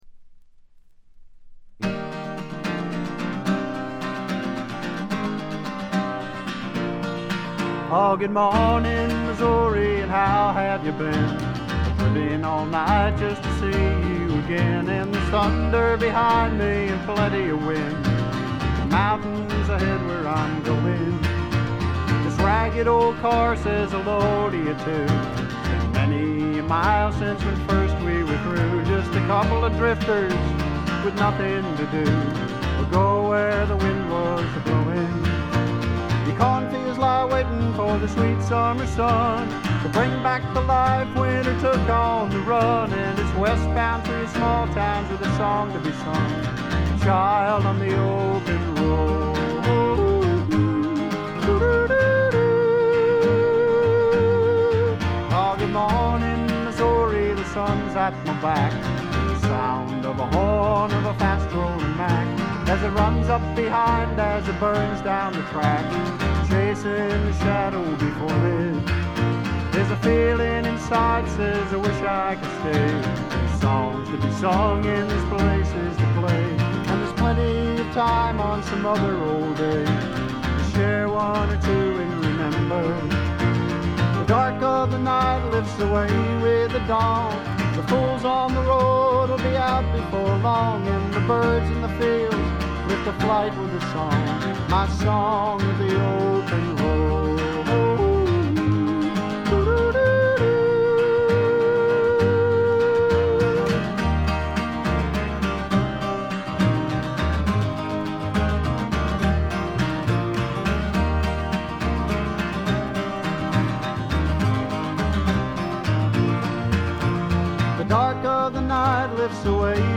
ほとんどノイズ感無し。
内容的にも前作路線で弾き語りに近いシンプルなバックのみで歌われる全14曲。
ロンサムで暖かい空気が部屋の中で静かに満ちていくような感覚がたまらないです。
試聴曲は現品からの取り込み音源です。